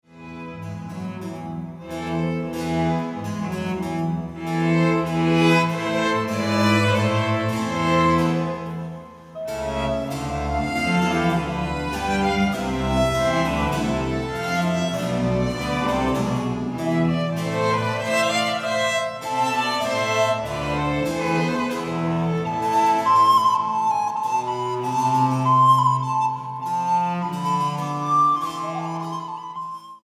para flauta, cuerdas y continuo